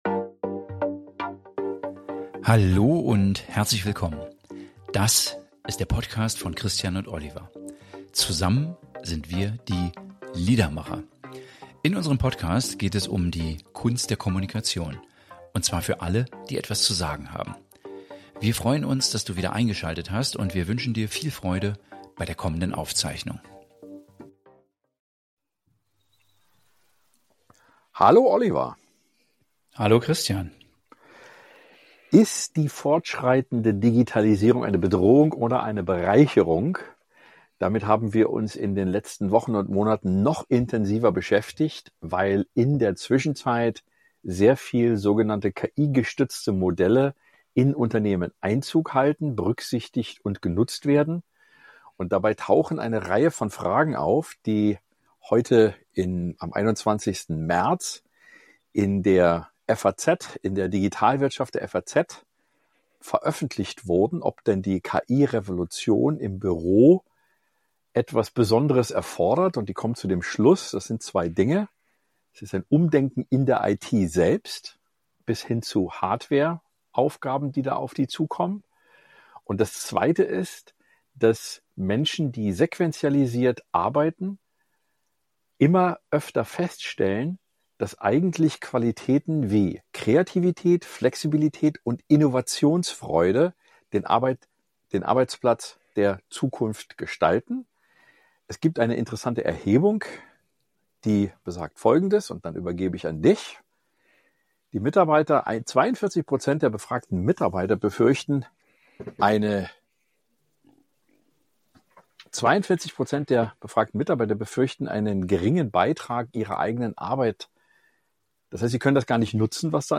Außerdem geht es um den Kulturwandel in Vertriebsorganisationen, persönliche Routinen und den Einfluss von KI auf Selbstorganisation, Motivation und Forecasting. Eine Folge vollgepackt mit Praxis, Haltung – und kleinen Werbe-Jingles in eigener Sache zwischendurch. 2.